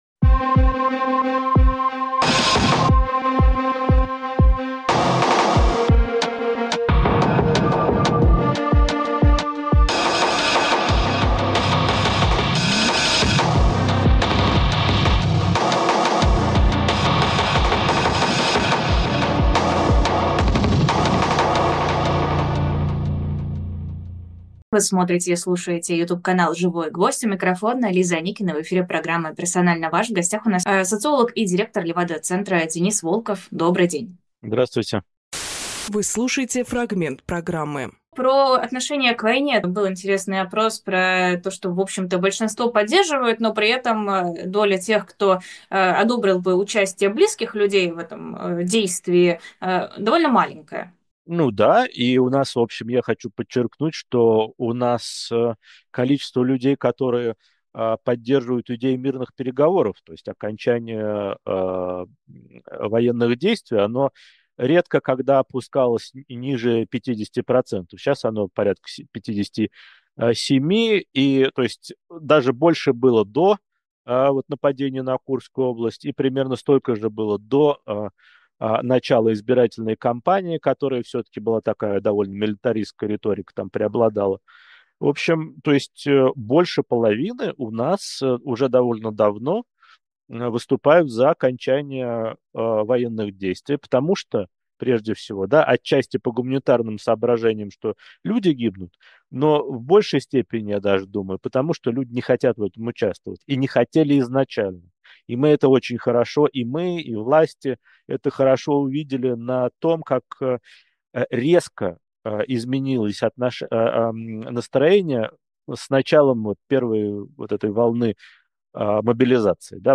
Фрагмент эфира от 13.12.24